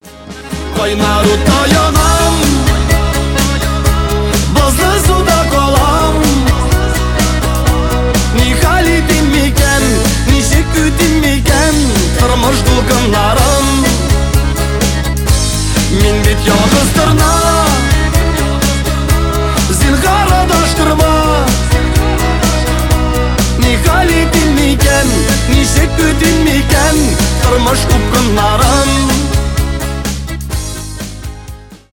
шансон , татарские , поп